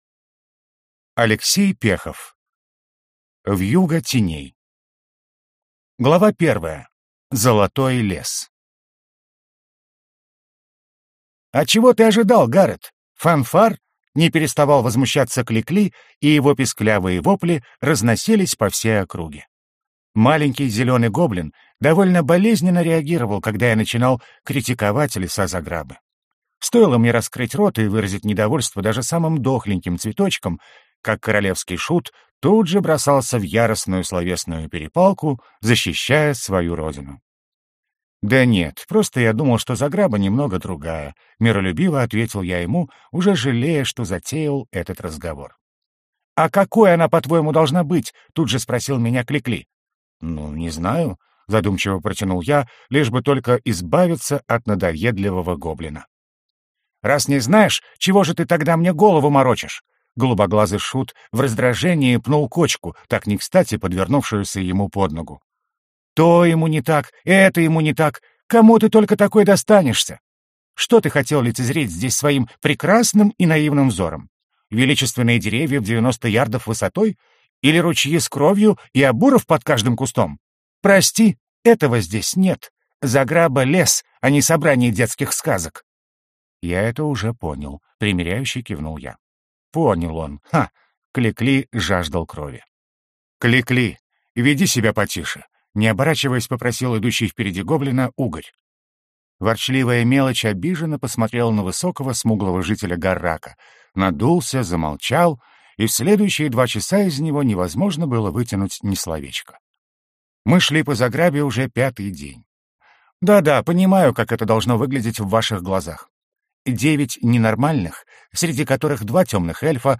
Аудиокнига Вьюга теней - купить, скачать и слушать онлайн | КнигоПоиск